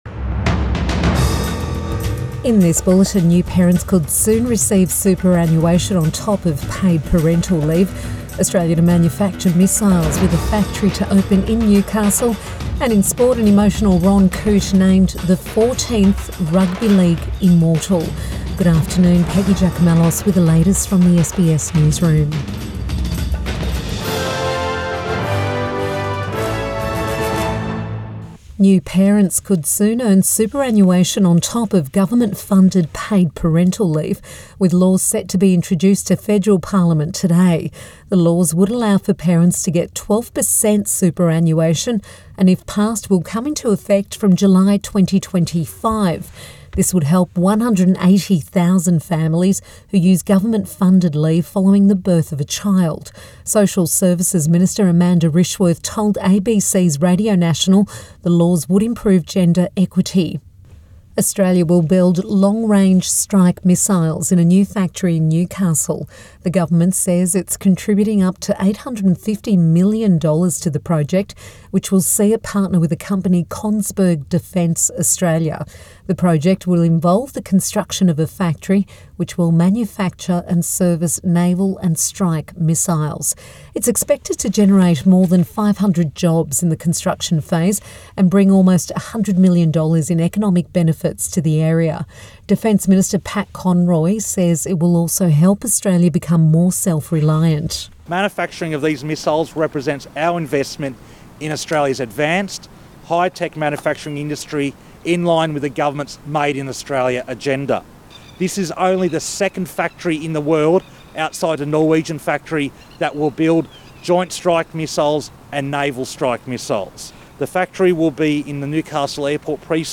Midday News Bulletin 22 August 2024